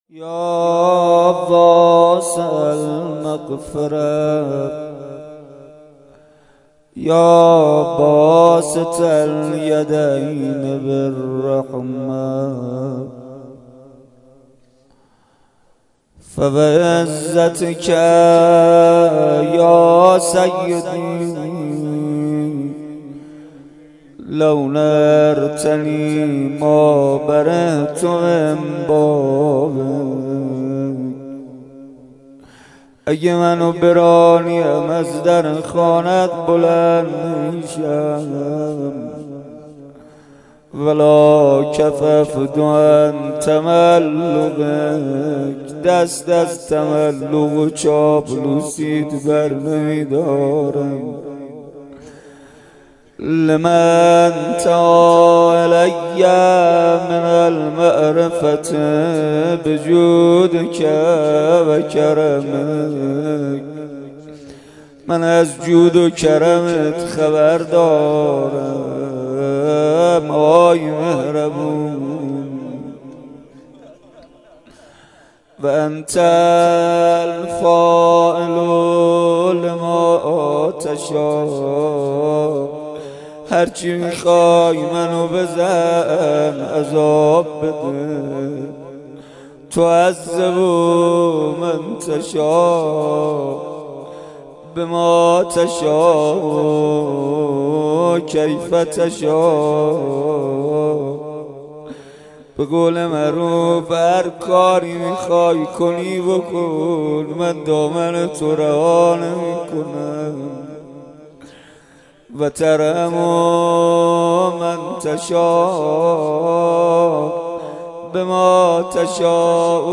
مناجات و روضه